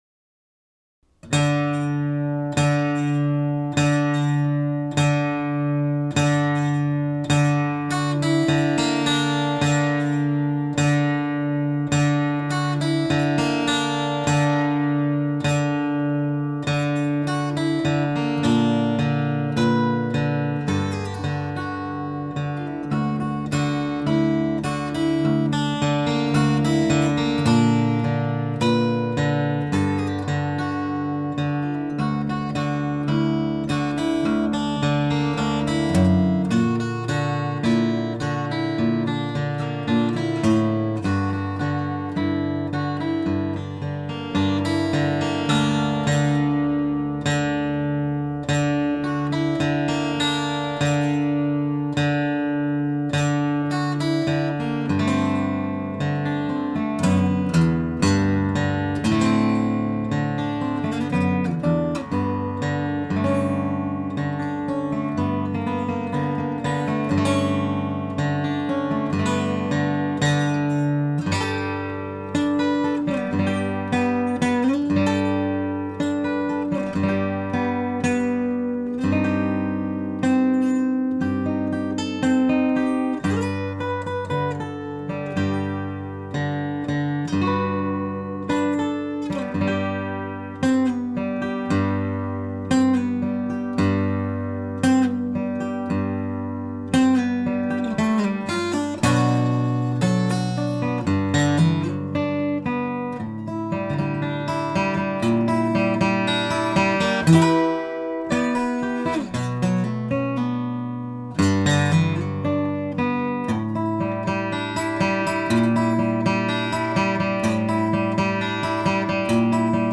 Since moving to Oakland, I’ve focused most of my creative energy on the guitar.